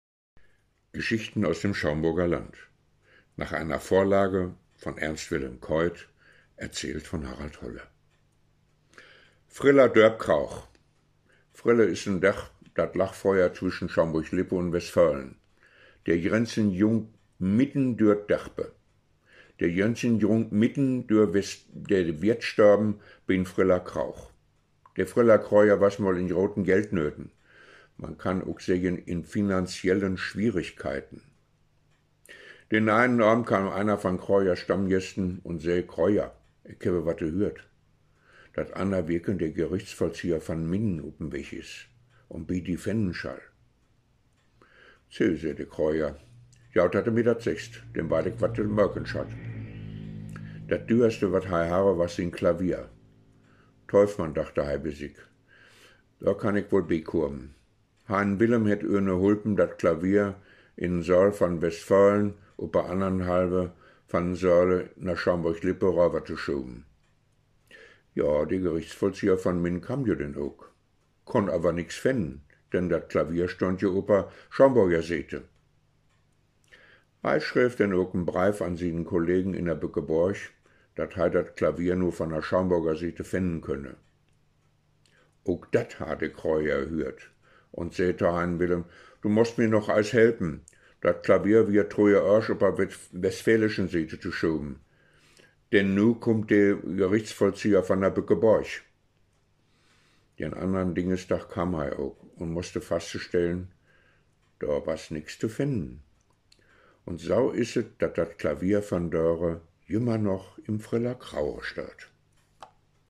Schaumburger Platt